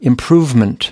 37. improvement (n) ɪmˈpruːvmənt/: sự cải tiến, cải thiện